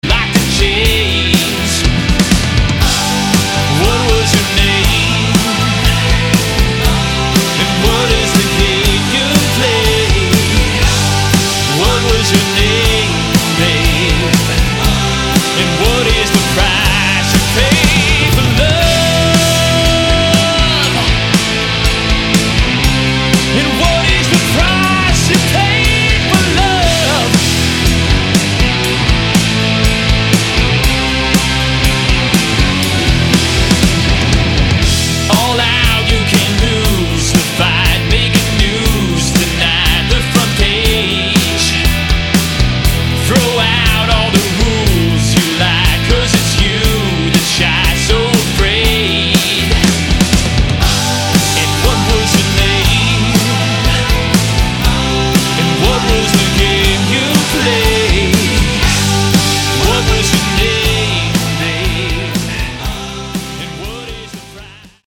LEAD GUITAR, RHYTHM GUITAR
BASS GUITAR
LEAD VOCALS
DRUMS
KEYBOARDS